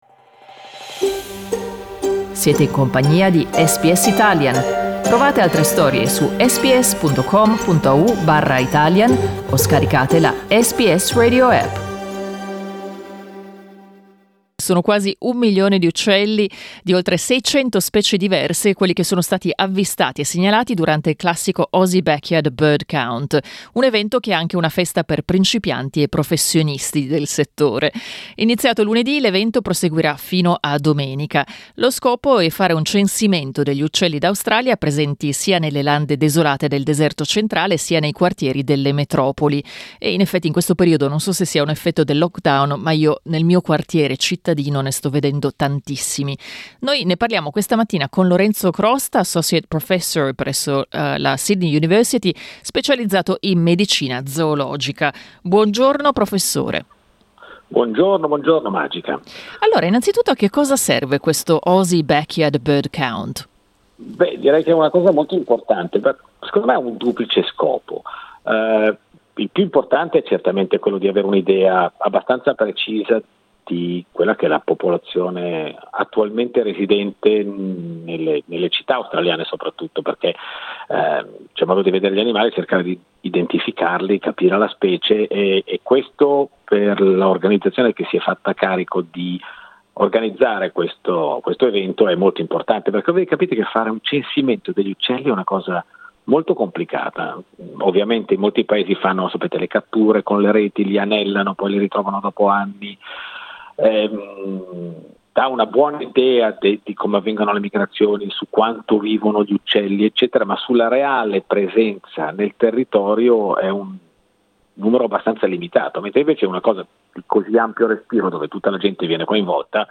Ascolta l'intervista: LISTEN TO Il censimento degli uccelli in Australia dal giardino di casa SBS Italian 14:11 Italian Le persone in Australia devono stare ad almeno 1,5 metri di distanza dagli altri.